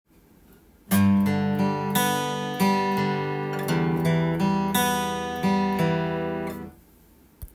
アコースティックギターで出来る【アルペジオの作り方】
アルペジオ例
例の①～④すべて　ルート音からアルペジオが始まっています。